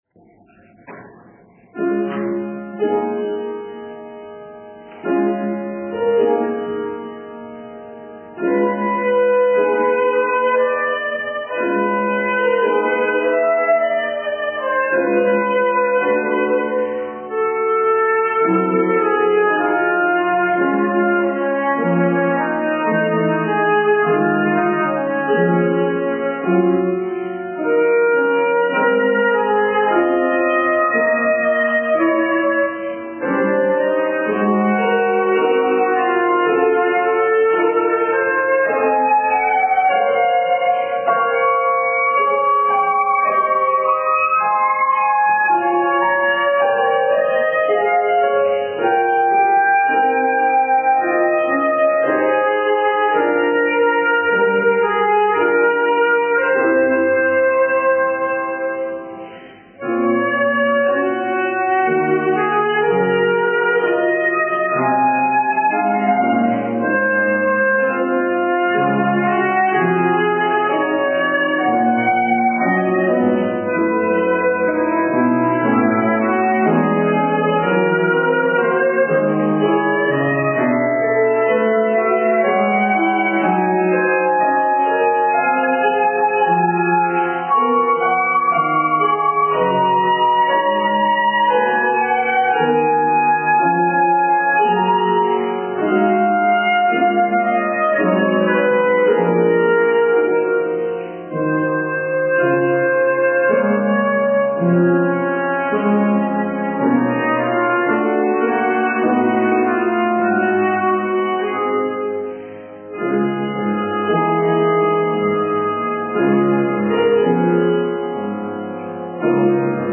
Ogólnopolskiego Konkursu Interpretacji Muzycznej
flet